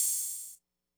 hihat03.wav